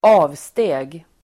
Ladda ner uttalet
Uttal: [²'a:vste:g]